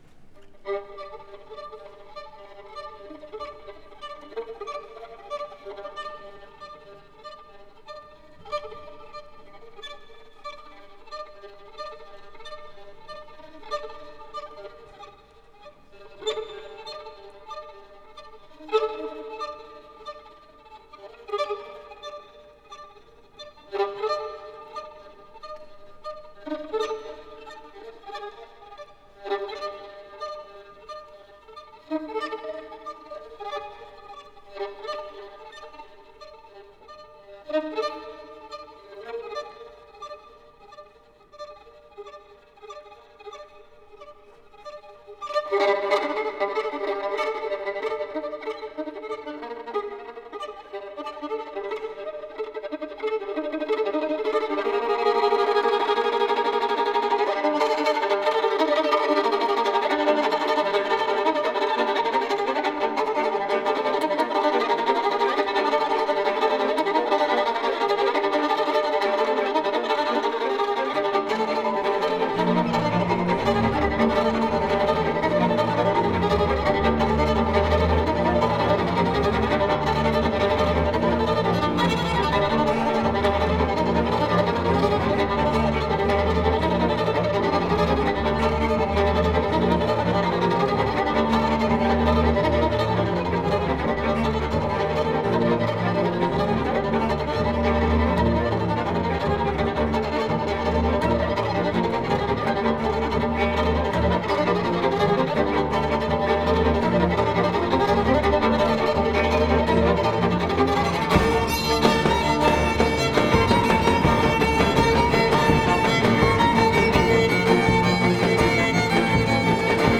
a lengyel vonós kvintett